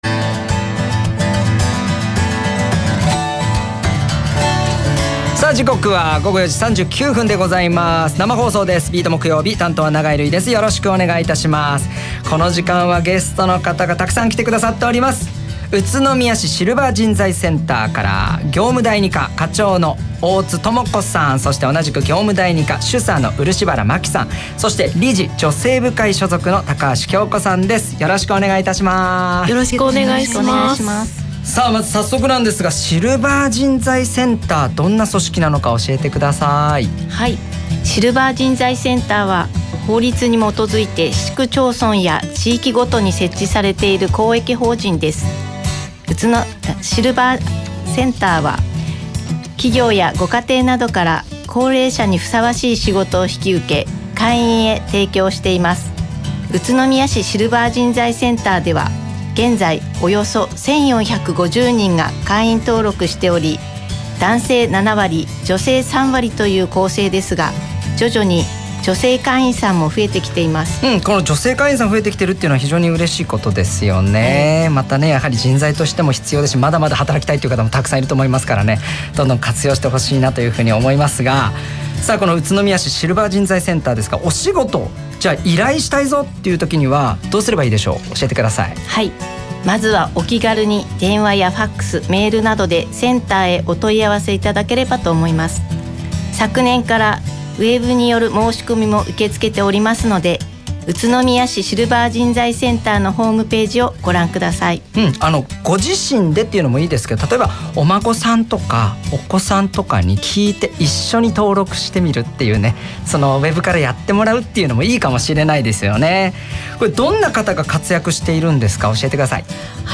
3/13（木）RADIO BERRYの生放送に「Ｂ・Ｅ・Ａ・Ｔ」 宇都宮市SCが出演しました。
宇都宮市SCで活躍中の会員さんと職員さんがFM栃木放送のラジオ番組に生出演し、シルバー人材センターの魅力を生の声で楽しくお届けしてくれました。 その他、宇都宮市SCのキャラクター「ちょこちゃん」や女性に人気のイベント等についても紹介し、シルバー人材センターを知って頂く機会となりました。